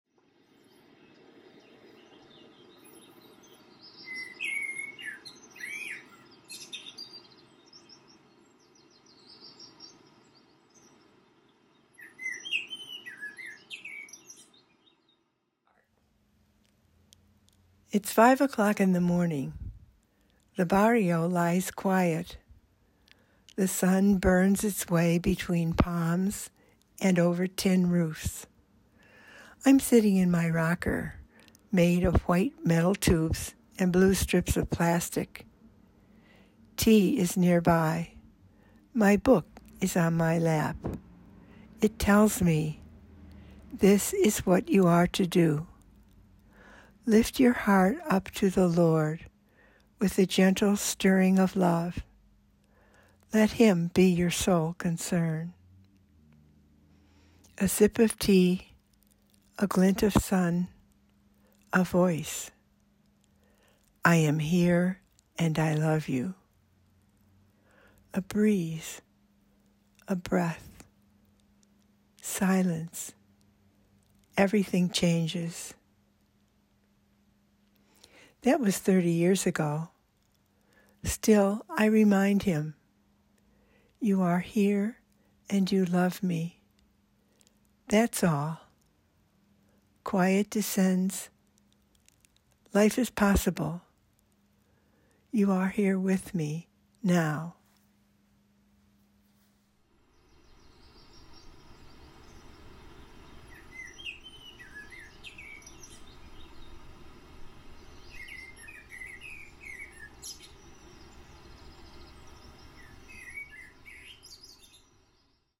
In October 2024, the American Province launched Graced Moments, where every month, a Holy Child Sister shares a “grace-filled” experience that has influenced her life.